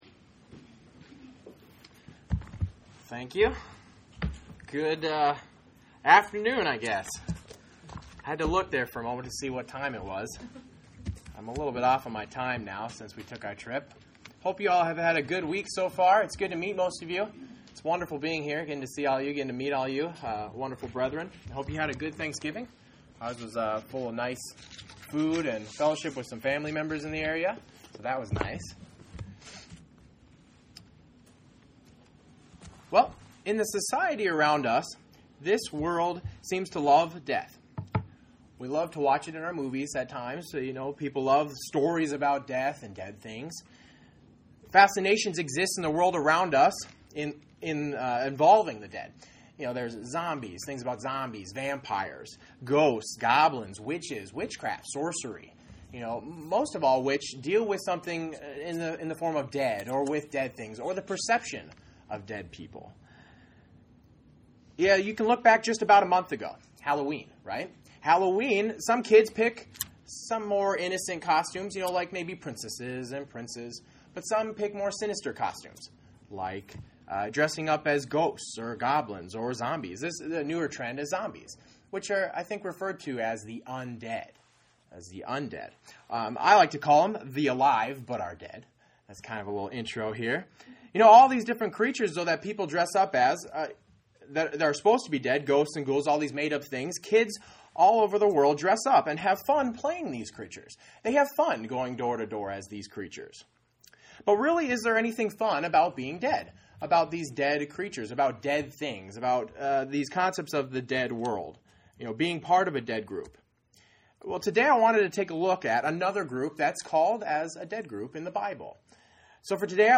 Given in Columbia, SC
UCG Sermon Studying the bible?